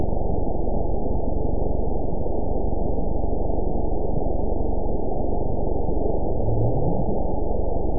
event 922604 date 02/06/25 time 23:53:03 GMT (2 months, 3 weeks ago) score 9.24 location TSS-AB02 detected by nrw target species NRW annotations +NRW Spectrogram: Frequency (kHz) vs. Time (s) audio not available .wav